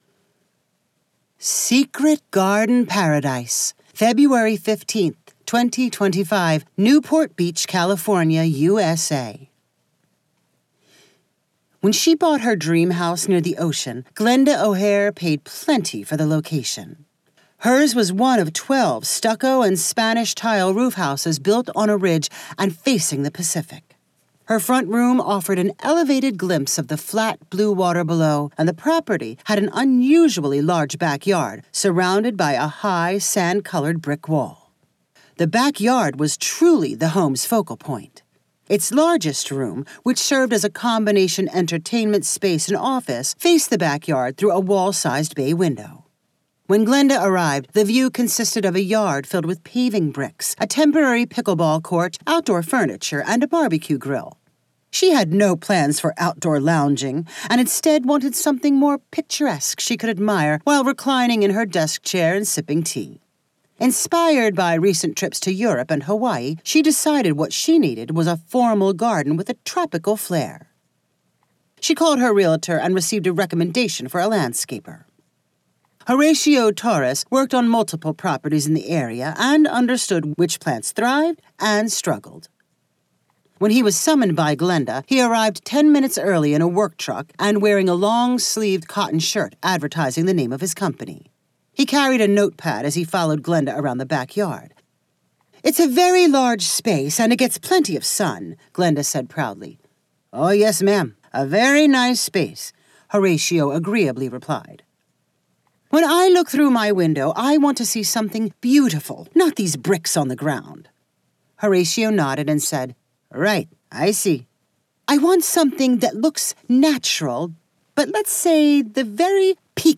Short story with text and audio.